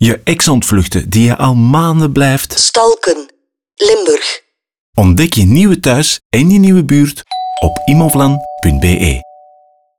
Radiospot_Immovlan_Stalken